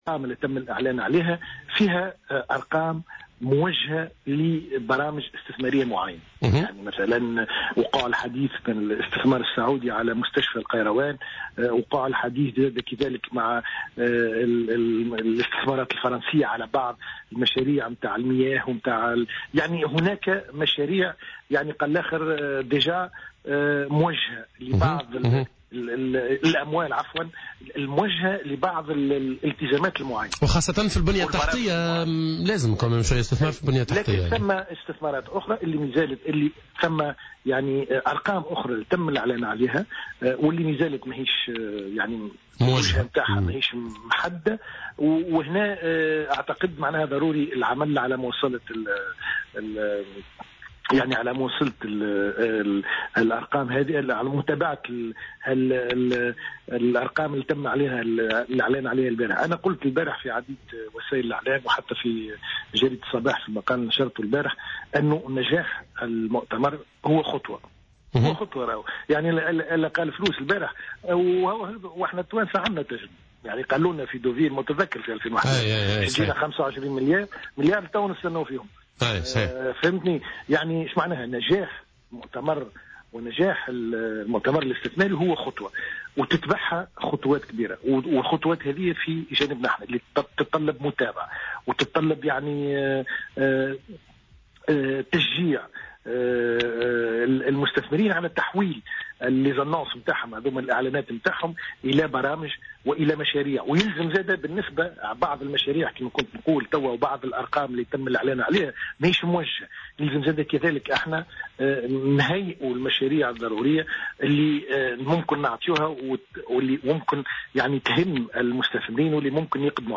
قال وزير المالية السابق، حكيم بن حمودة إن الأرقام التي تم الإعلان عنها أمس خلال مؤتمر الاستثمار فيها اعتمادات موجهة لإنجاز بعض المشاريع المعينة و أخرى لم تحدّد وجهتها بعد.